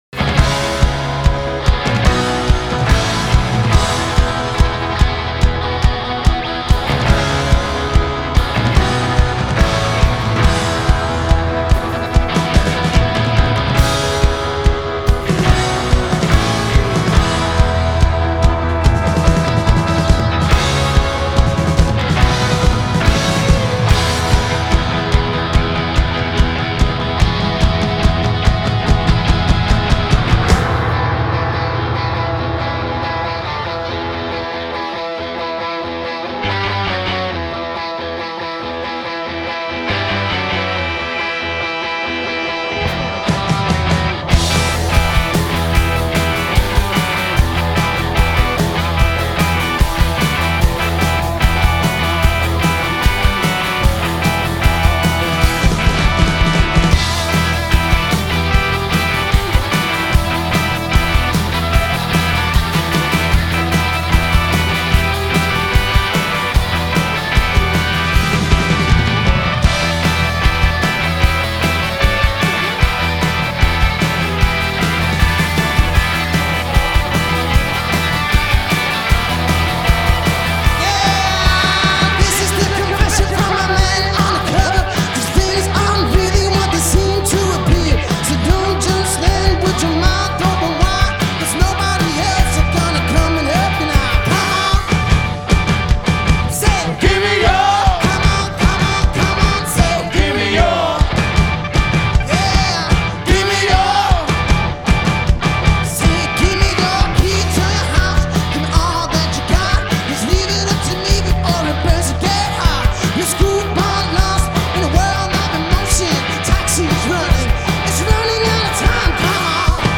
Hard Rock revival?